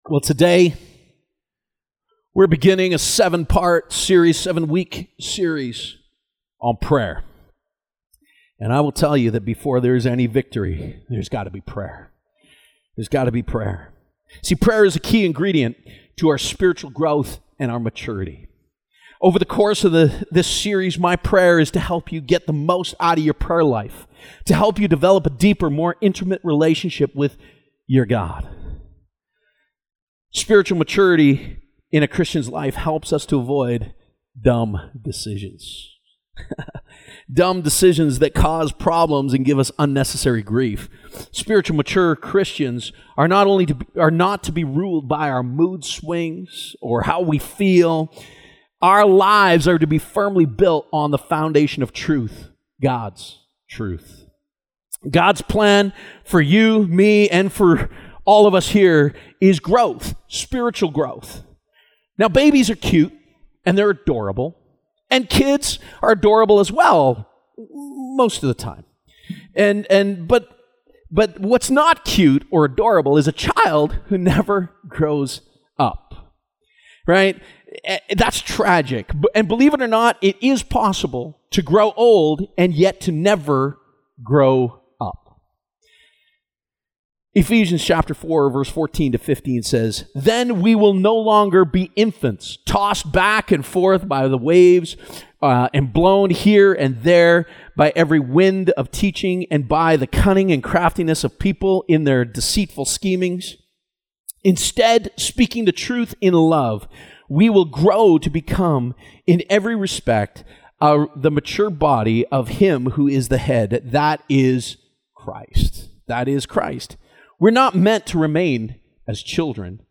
Sermons | Abundant Life Chapel